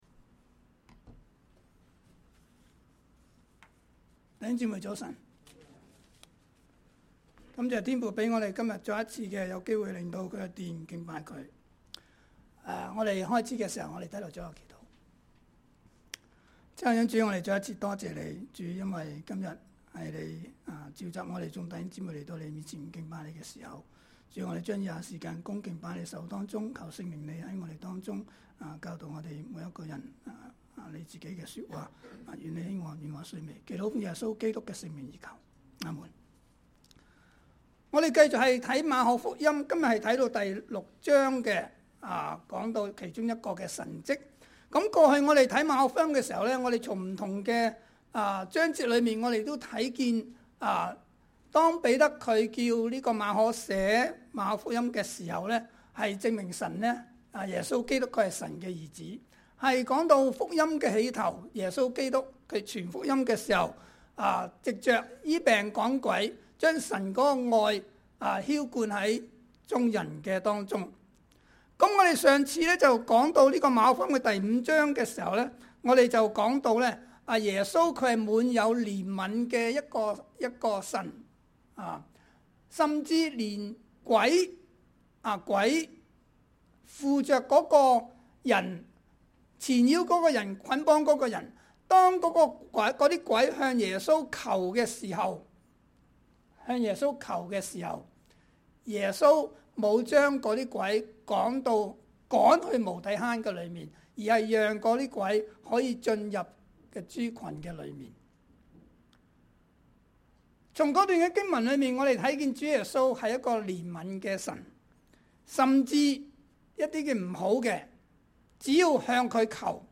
RCCC_Sunday_Sermon_2020_02_16